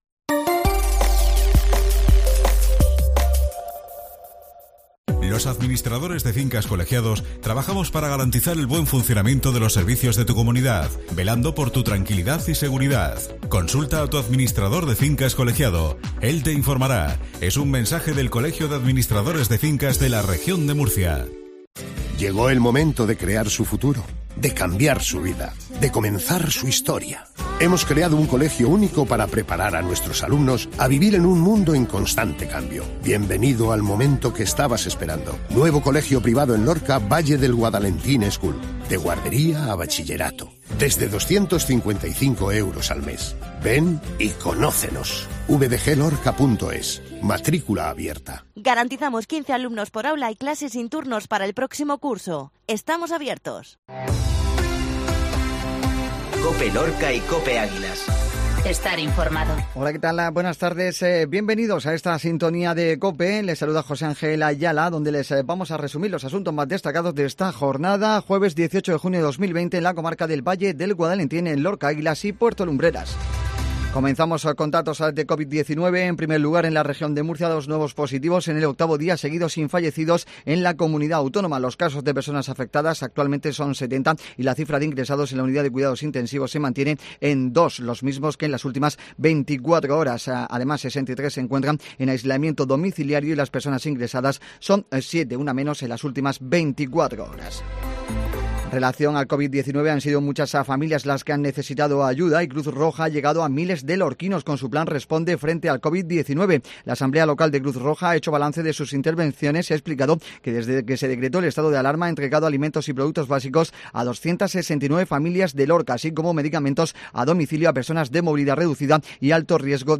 INFORMATIVO MEDIODIA COPE LORCA